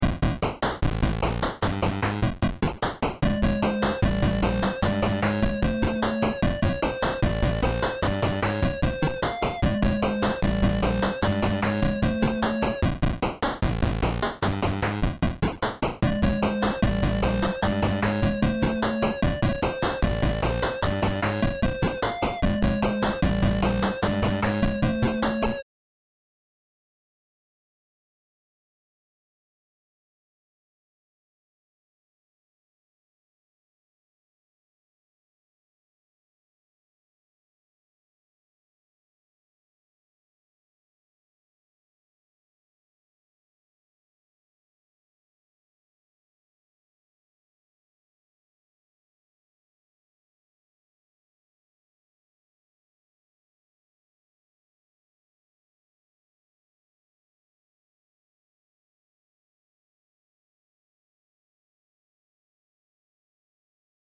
A nice music piece in 8-bit retro style.